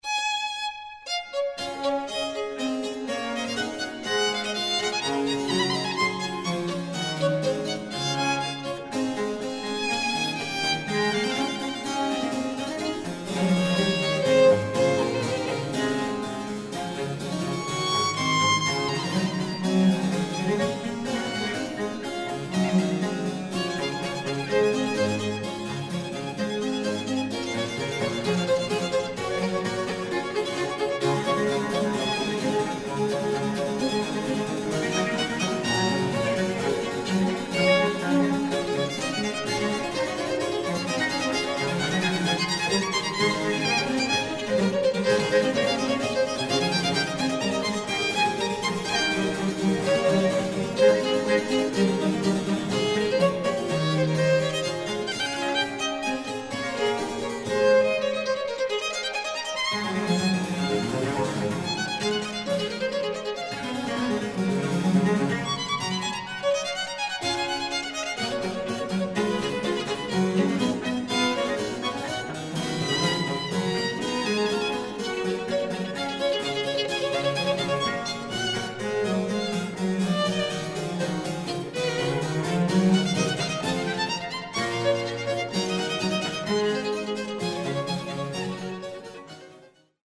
Complete Sonatas for Violin and basso continuo
baroque violin
Original Instruments